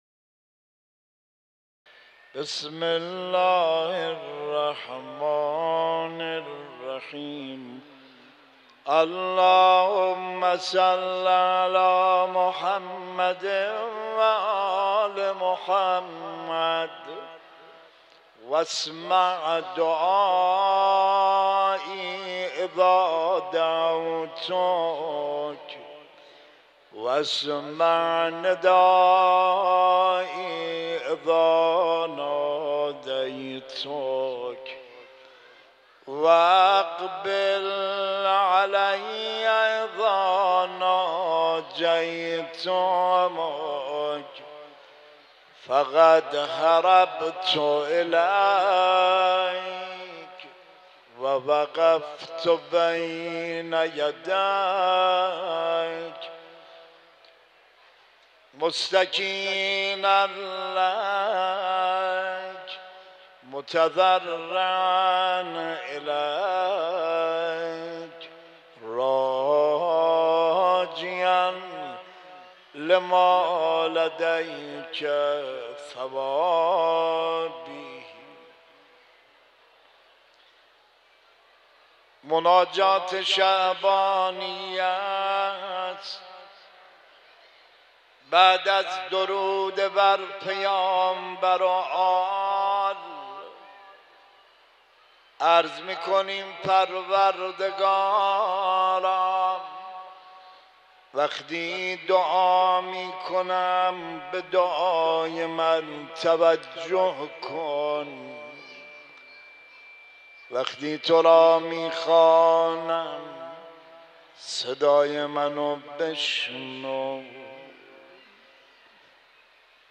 # دعا